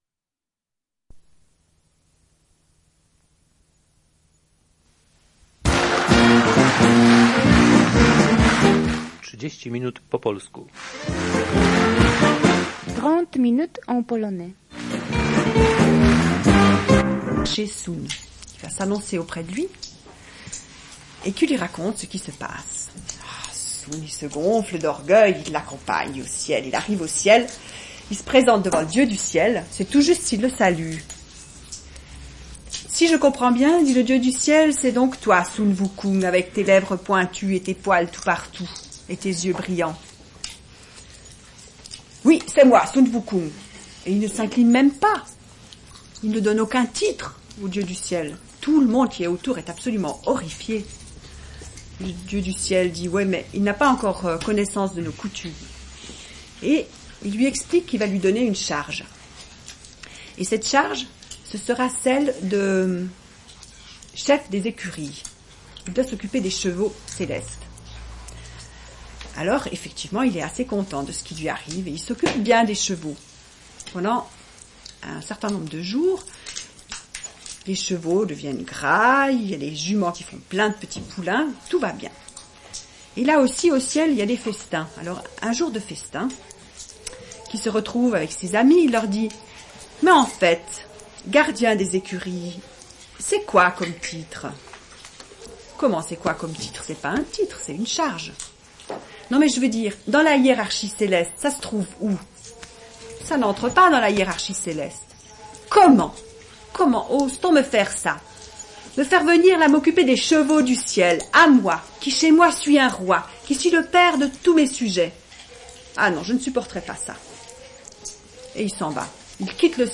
Rush Enregistrement sonore